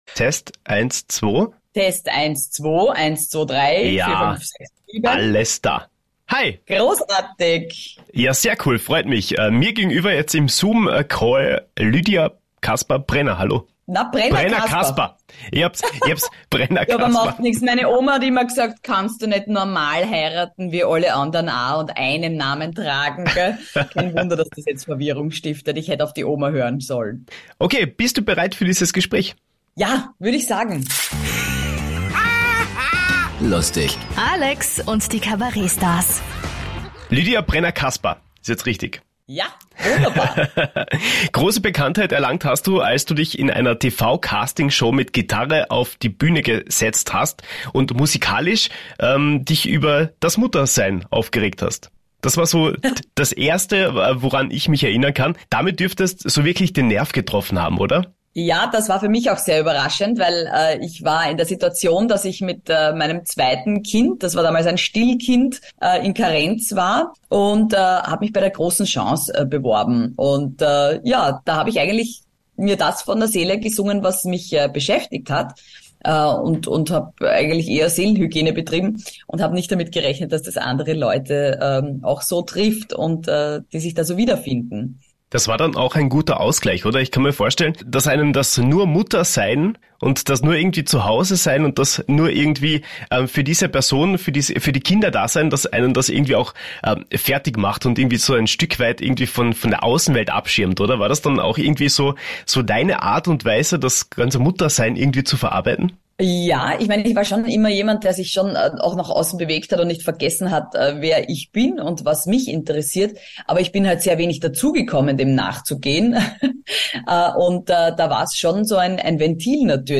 Lydia Prenner-Kasper kann Ernst, aber natürlich auch lustig. In der aktuellen Folge unseres Ha Ha Lustig Podcast hat uns die Witzeerzählerin aus dem Fernsehen natürlich auch einen Witz erzählt.